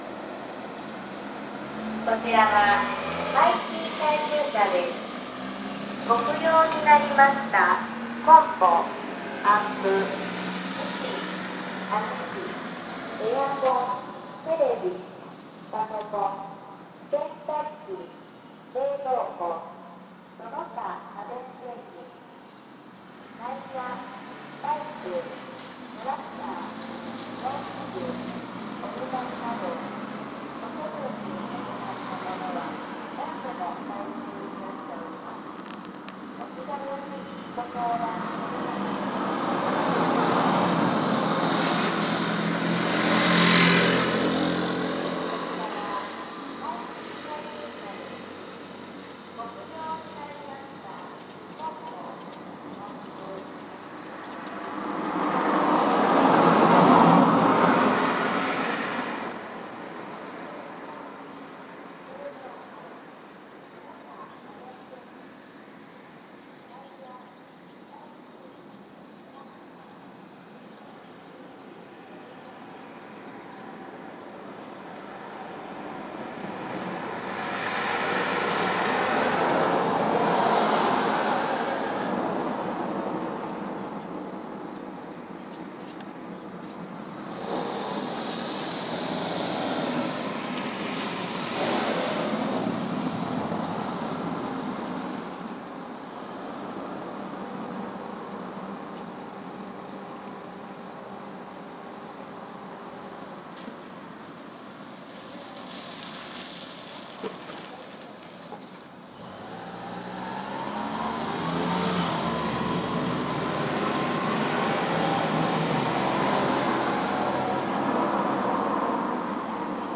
RecyclingCollectionTruckatHayashiKita.mp3